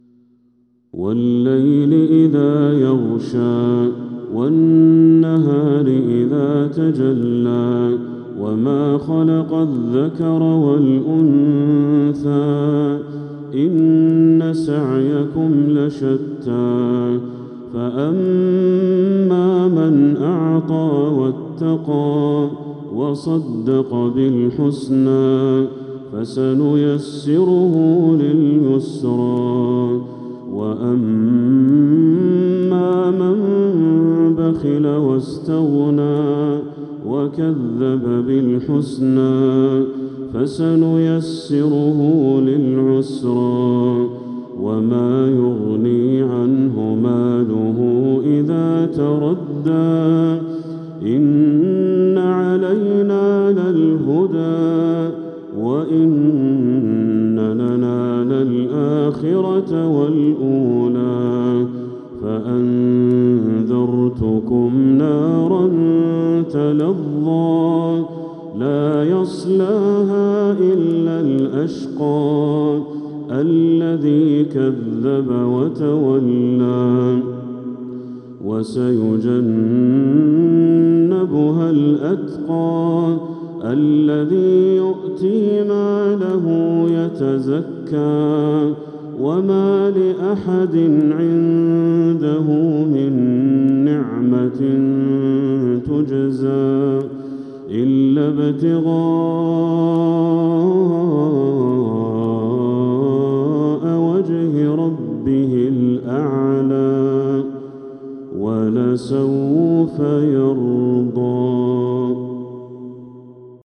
سورة الليل كاملة | محرم 1447هـ > السور المكتملة للشيخ بدر التركي من الحرم المكي 🕋 > السور المكتملة 🕋 > المزيد - تلاوات الحرمين